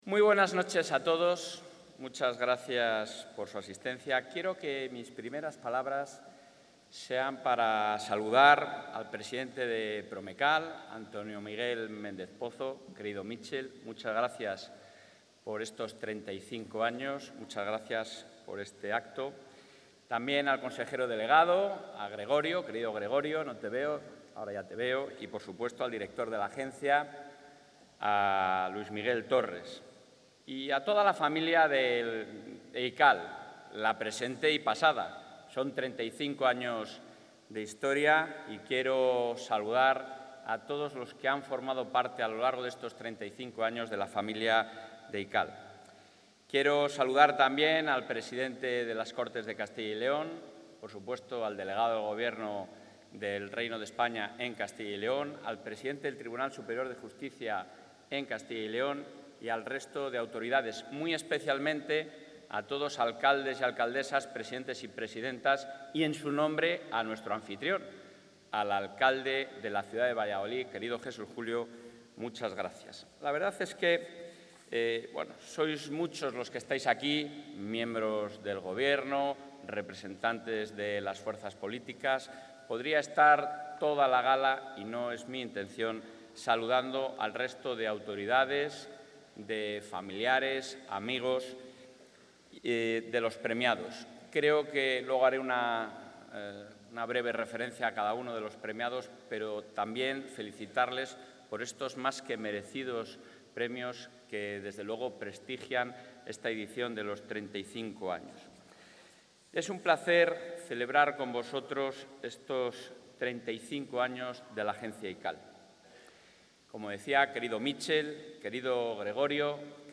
Intervención del presidente de la Junta.
En la gala del XXXV aniversario de la Agencia ICAL, el presidente de la Junta ha señalado que, en la actualidad, son más necesarios que nunca medios de comunicación y profesionales objetivos que controlen la labor de todos los poderes públicos.